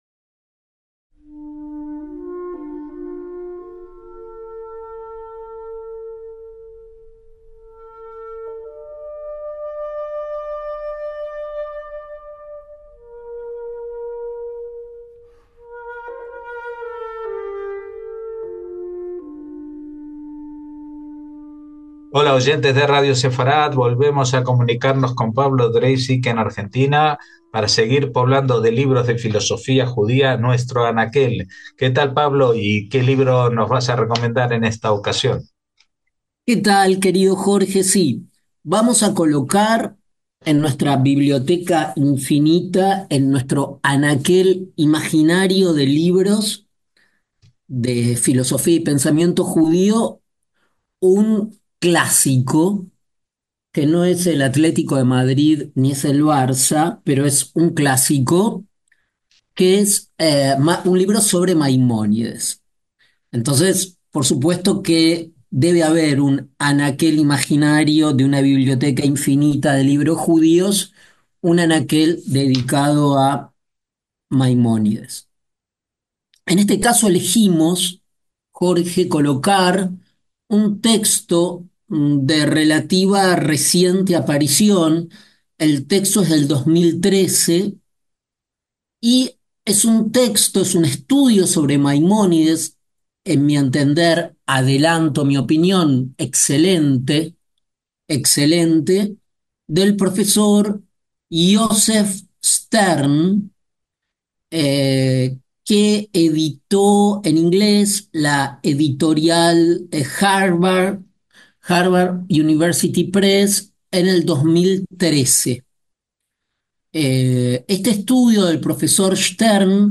Recorded Lectures